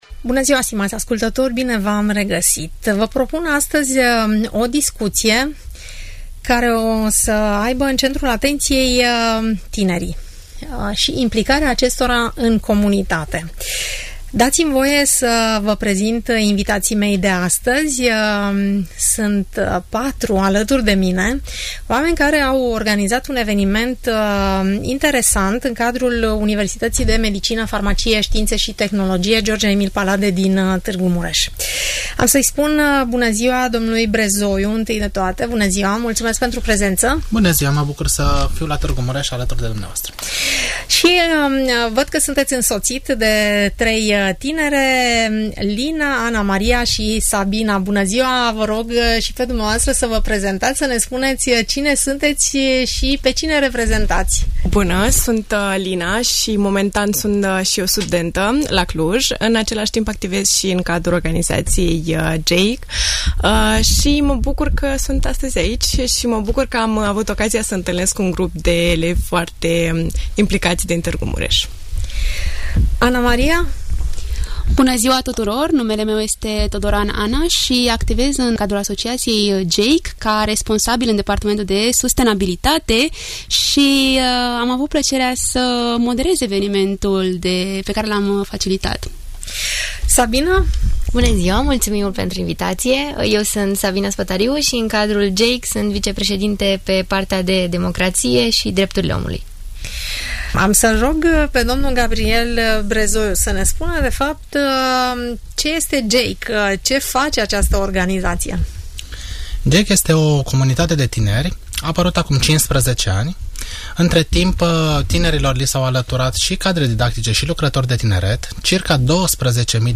Mureș pentru toate acestea aflați de la reprezentanții Organizației GEYC , invitați la RTM în emisiunea "Părerea ta"